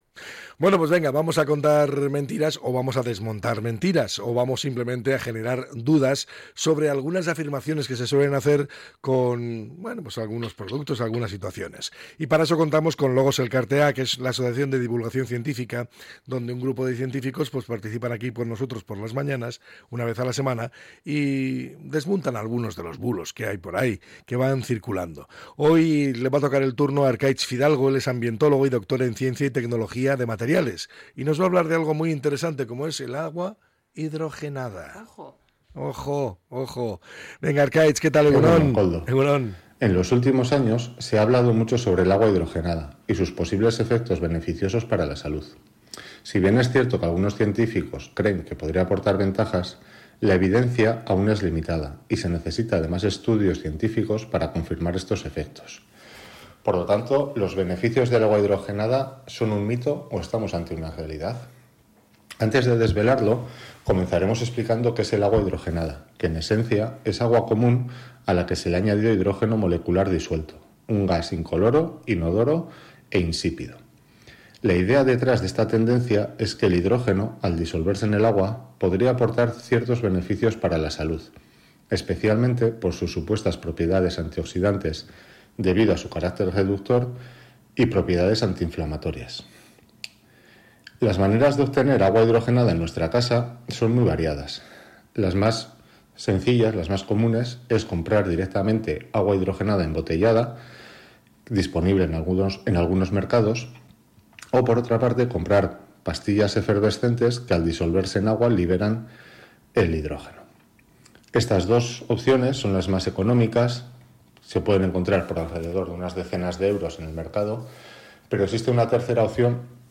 En el espacio de divulgación científica Logos Elkartea, emitido en Radio Popular – Herri Irratia